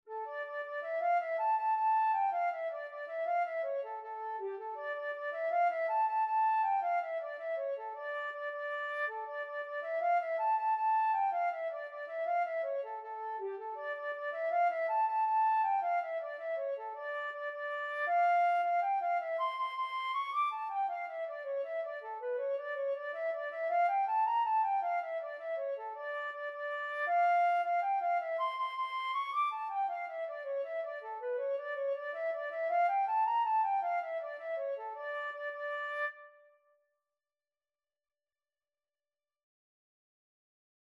Flute version
Traditional Music of unknown author.
D minor (Sounding Pitch) (View more D minor Music for Flute )
6/8 (View more 6/8 Music)
G5-D7
Traditional (View more Traditional Flute Music)